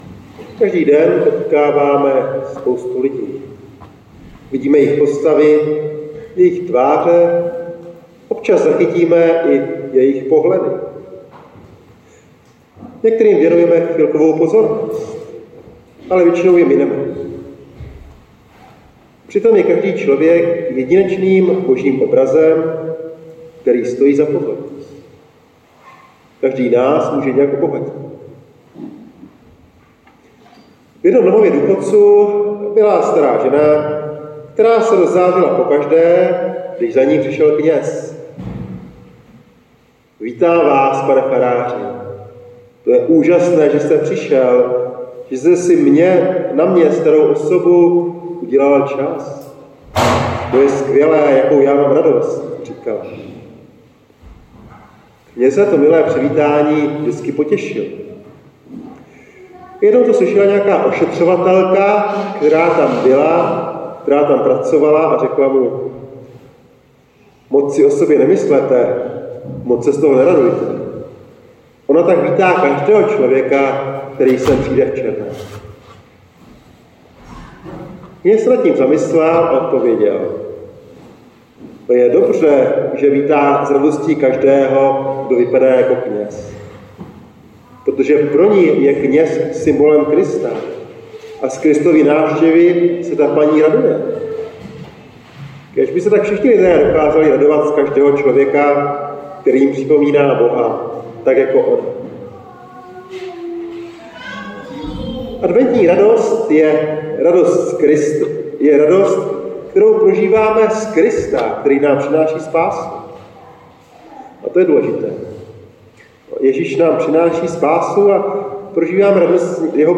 Kázání z 3. neděle adventní (5.1 min.)